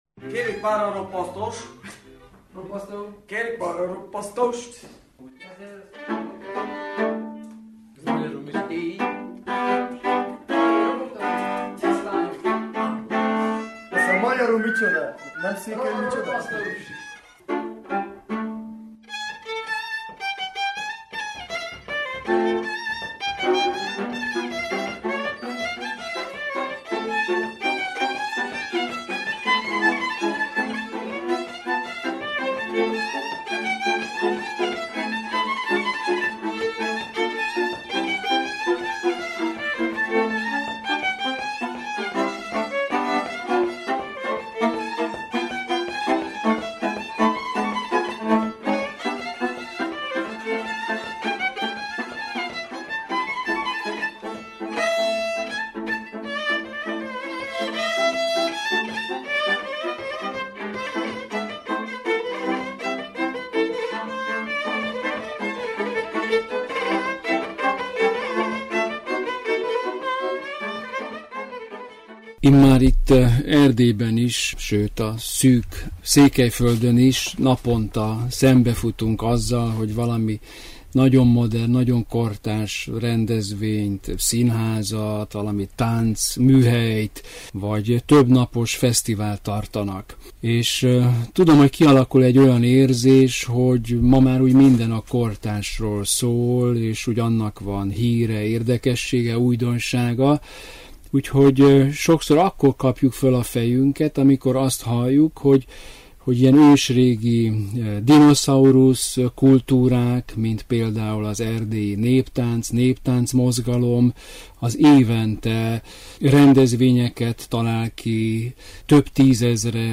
Meghívó a KULTÚRPRESSZÓban a szervezőktől: